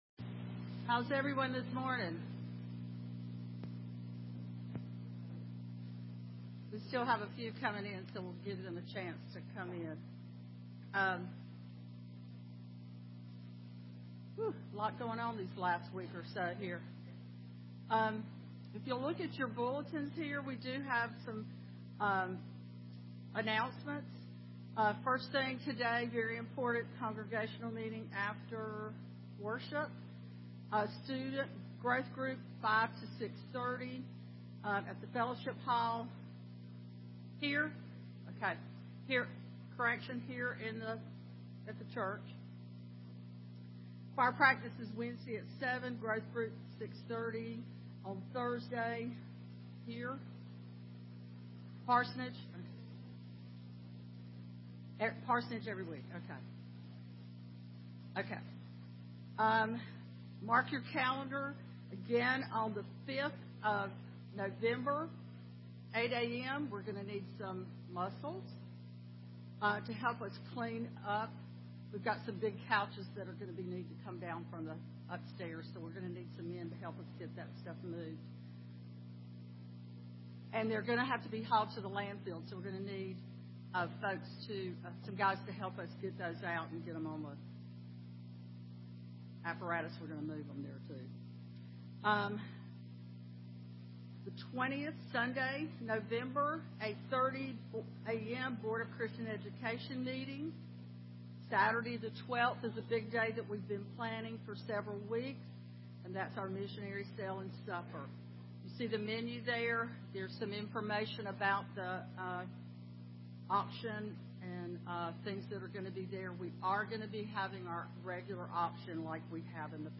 Please excuse the audio problems